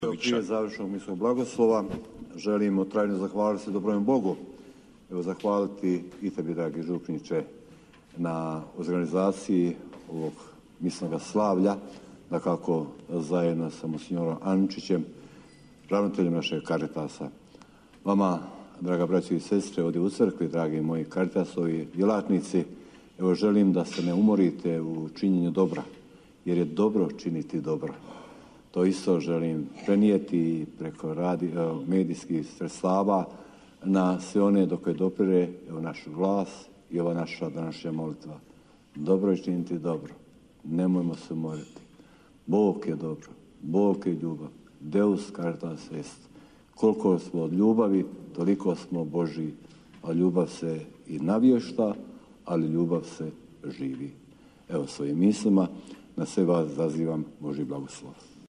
Euharistijsko slavlje u okviru Tjedna solidarnosti, koji se ove godine odvija pod motom „Solidarnost u deset riječi!“, izravno je prenosila Hrvatska Radiotelevizija na svom prvom kanalu, a izravni prijenos preuzimala je i RTV Herceg-Bosna.
Riječi zahvale svima uputio je i biskup Majić prije završnog misnog blagoslova te svima, a posebno karitativnim djelatnicima poželio da se ne umore u činjenju dobra, jer je dobro činiti dobro.